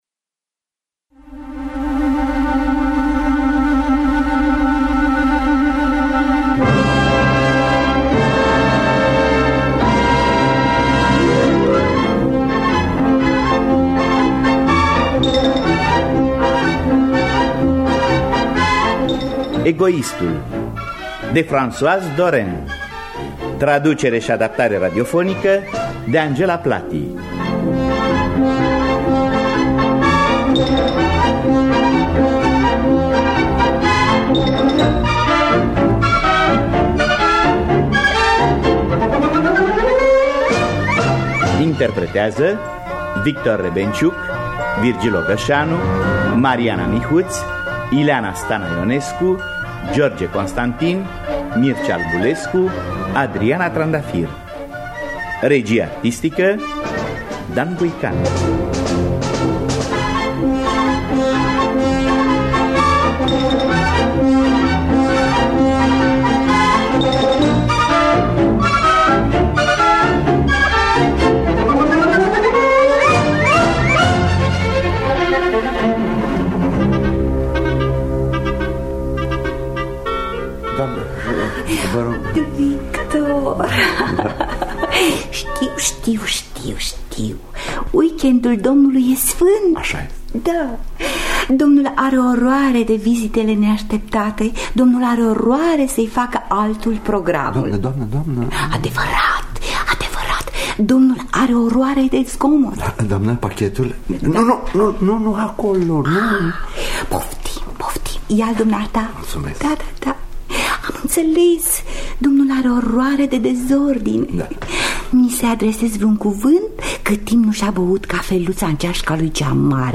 În distribuție: Victor Rebengiuc, Mariana Mihuț, Virgil Ogășanu, Ileana Stana-Ionescu, George Constantin, Mircea Albulescu, Adriana Trandafir.